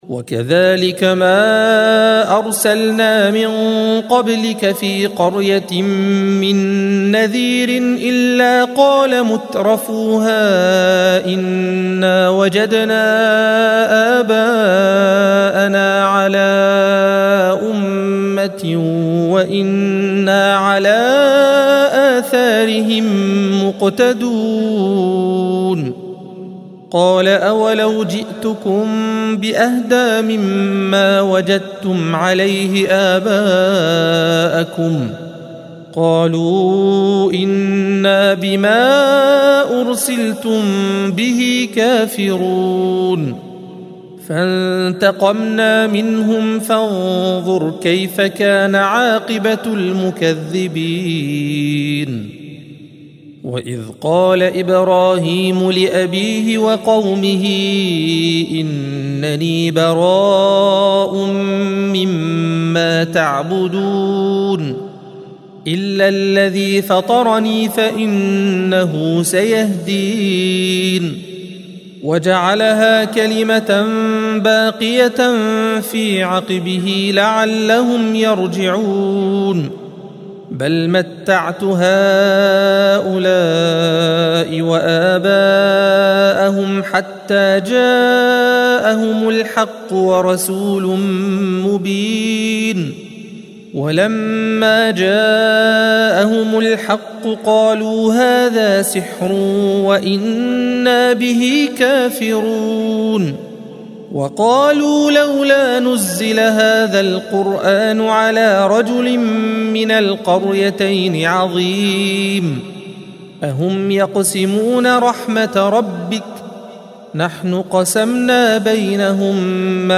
الصفحة 491 - القارئ